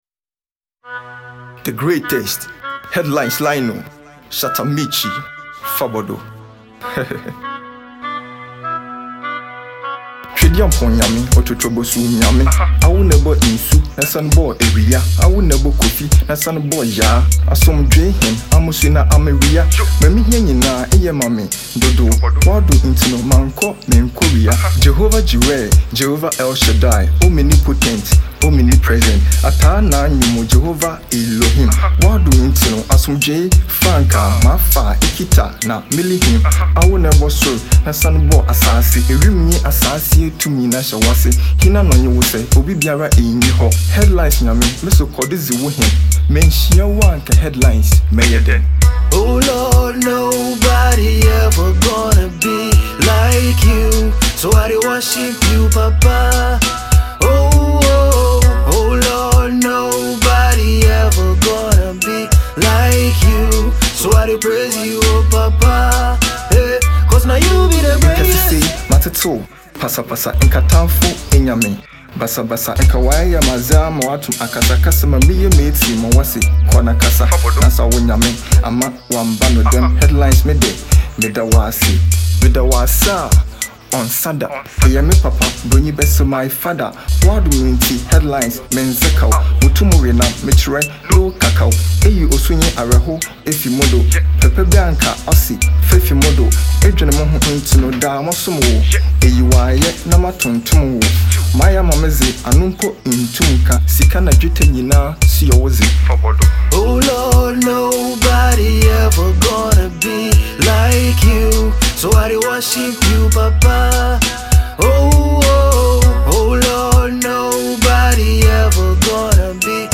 New Gospel HipHop song